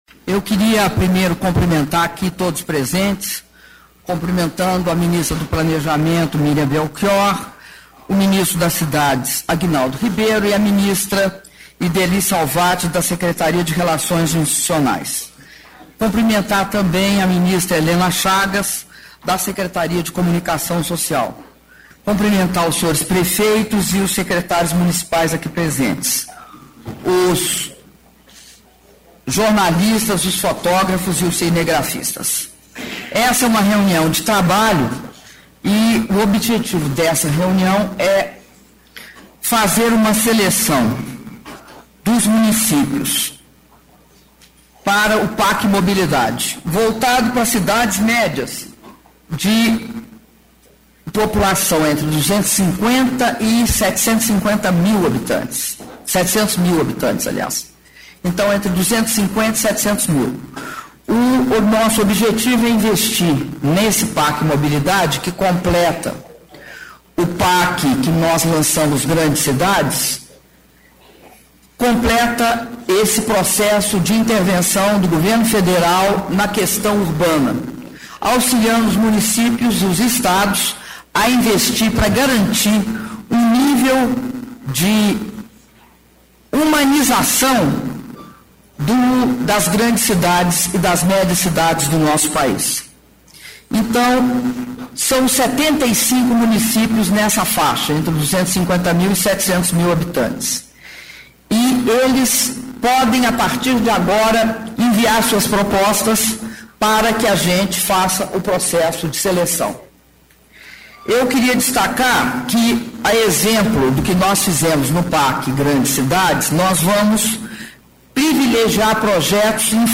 Discurso da Presidenta da República, Dilma Rousseff, na apresentação do PAC Mobilidade Médias Cidades
Palácio do Planalto, 19 de julho de 2012